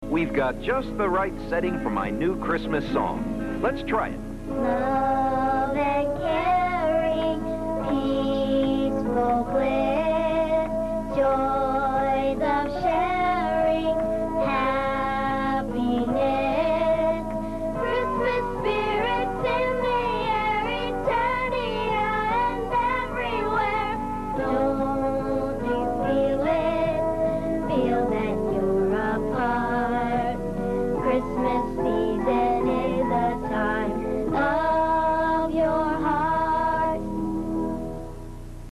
a hideous holiday song.